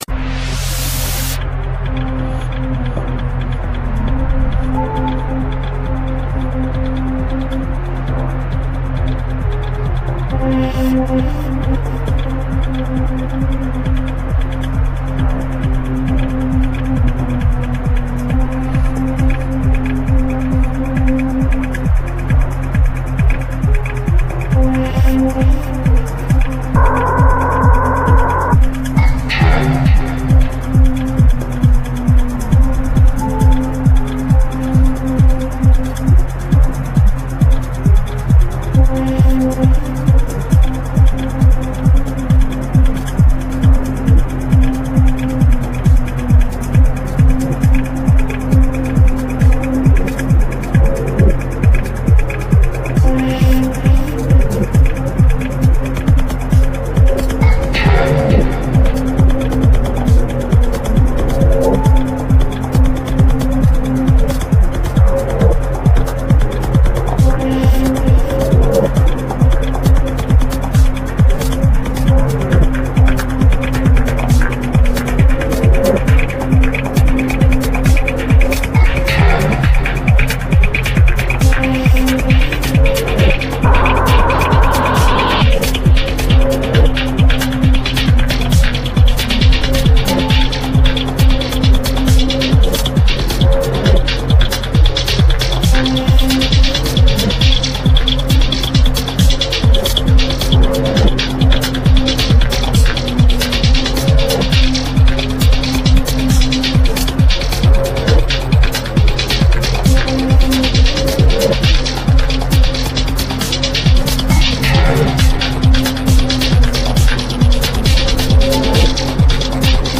La musique électronique est issue du funk